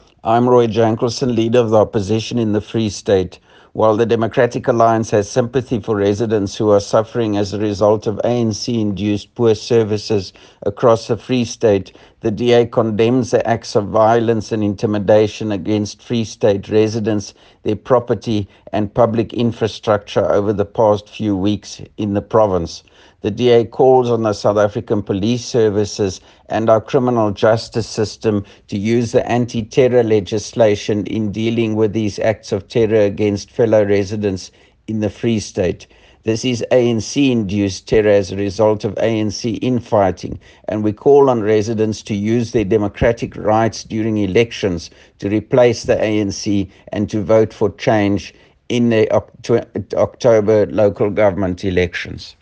Note to Editors: Please find the attached soundbites in
English and Afrikaans by Dr Roy Jankielsohn MPL, Leader of the Opposition in the Free State.